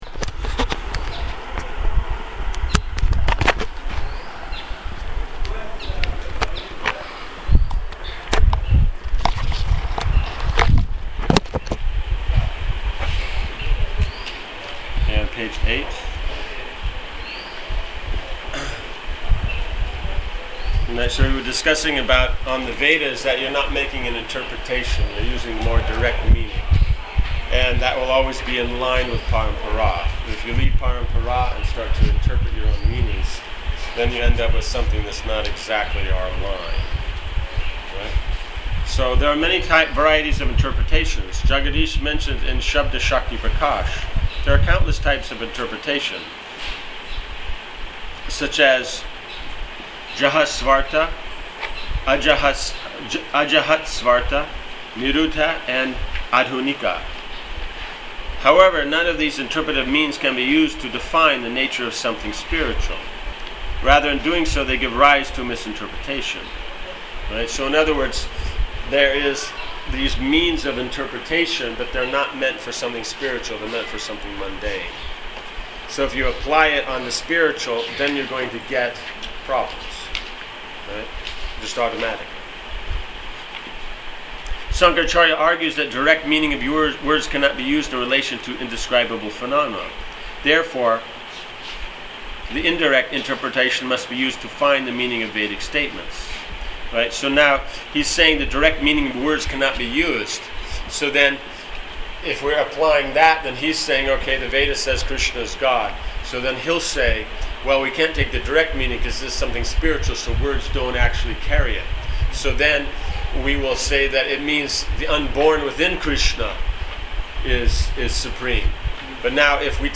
Transcription of lecture #3 of Śrī Śrī Caitaya Śikṣāmṛta and Daśa Mūla Tattva course, given in Bhaktivedānta Academy, Śrī Māyāpura